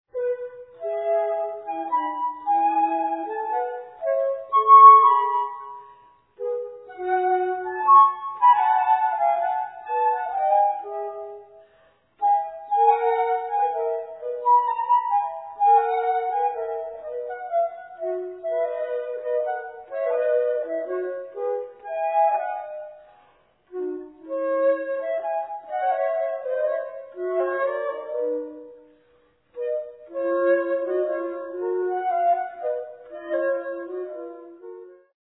sonata for 2 flutes No. 4 in F minor
Larghetto - 1:23